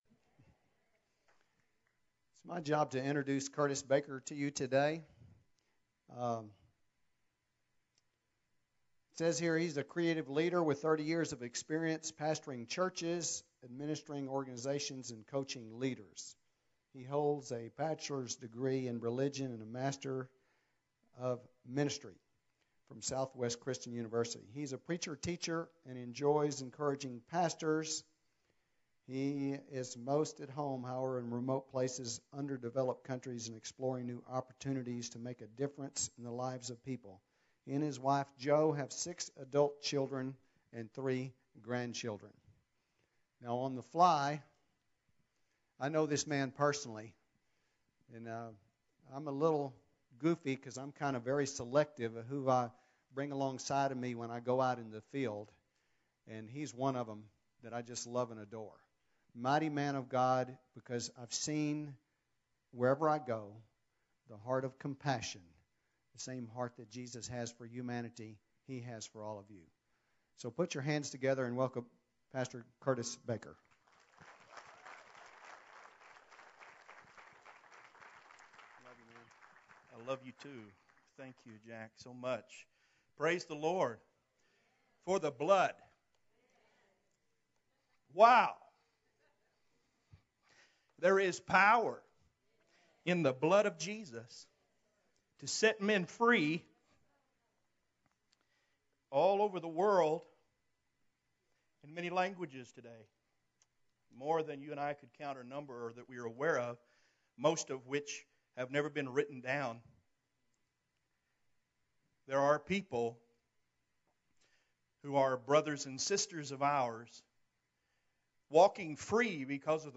Sermons Archive - Tree Of Life Church